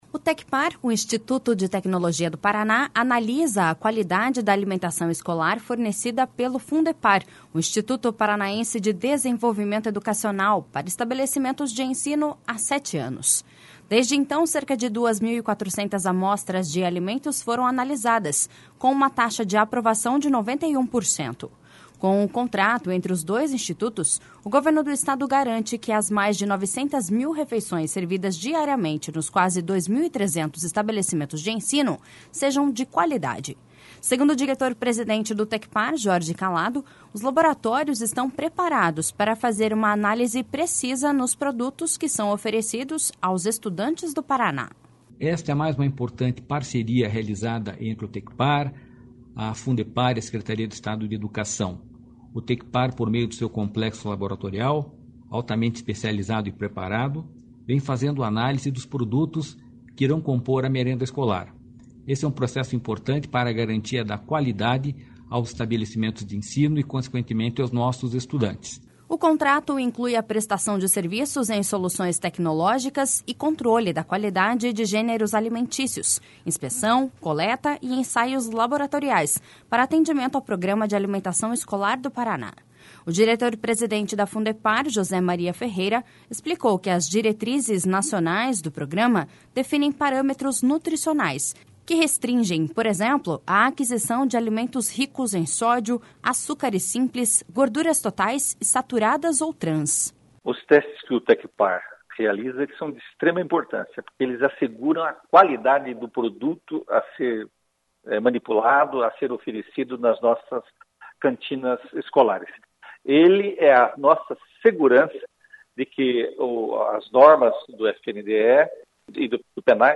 Segundo o diretor-presidente do Tecpar, Jorge Callado, os laboratórios estão preparados para fazer uma análise precisa nos produtos que são oferecidos aos estudantes do Paraná.// SONORA JORGE CALLADO.//
O diretor-presidente da Fundepar, José Maria Ferreira, explicou que as diretrizes nacionais do programa definem parâmetros nutricionais, que restringem, por exemplo, a aquisição de alimentos ricos em sódio, açúcares simples, gorduras totais e saturadas ou trans.// SONORA JOSÉ MARIA FERREIRA.//